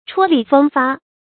踔厲風發 注音： ㄔㄨㄛ ㄌㄧˋ ㄈㄥ ㄈㄚ 讀音讀法： 意思解釋： 踔厲：精神振奮，言論縱橫；風發：象刮風一樣迅猛。